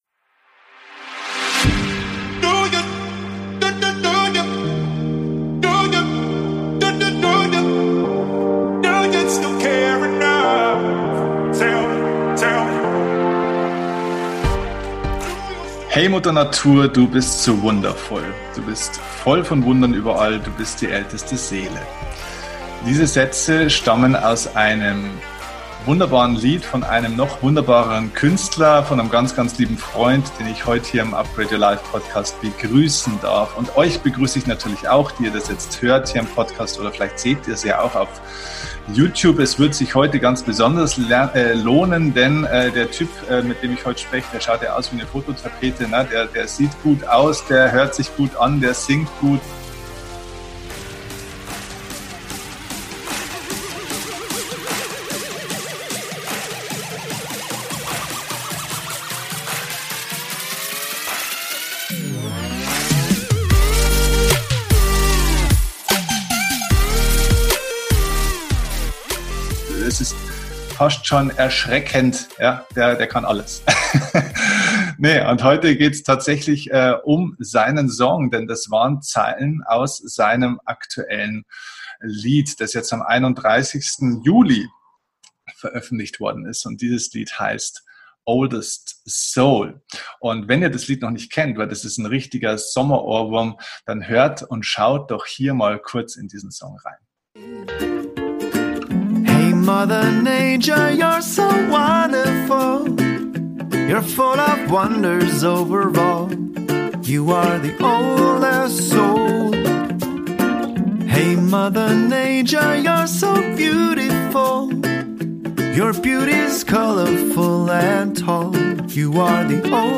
Beschreibung vor 5 Jahren #320 So lebst Du im Einklang mit der Natur - Interview